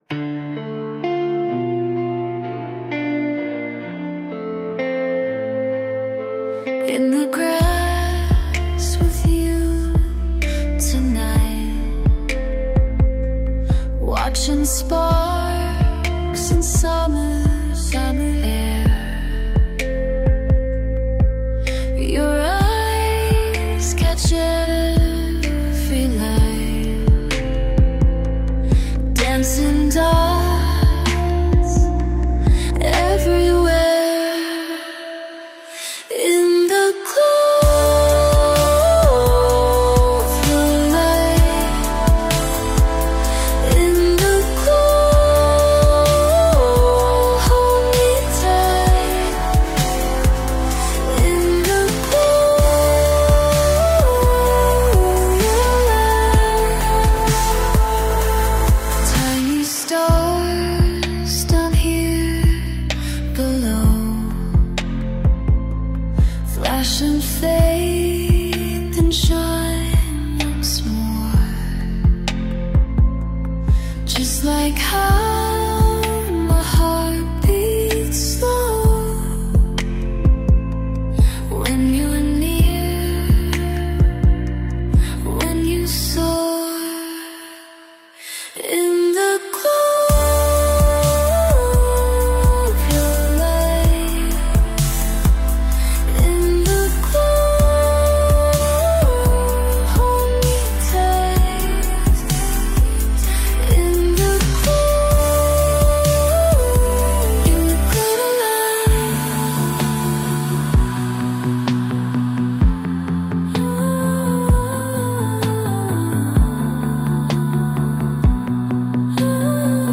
ListenWatch on YouTube  Avant-Garde, Dungeon synth